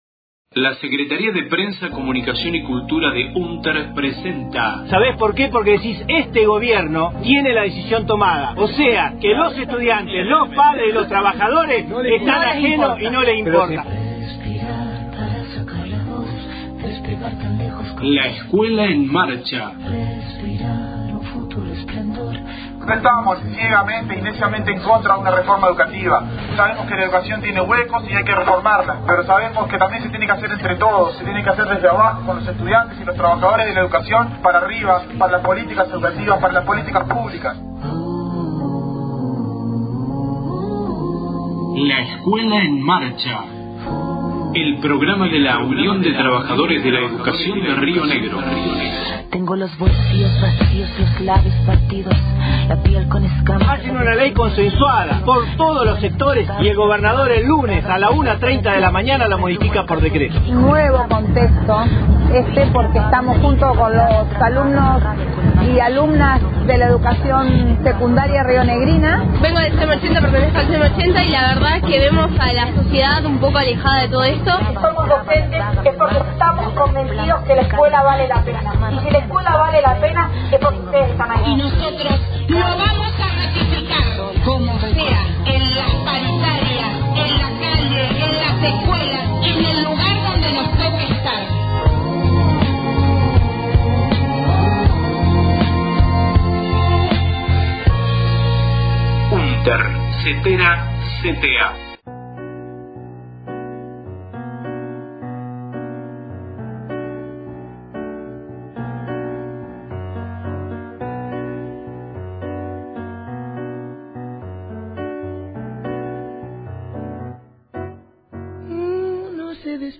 LEEM radio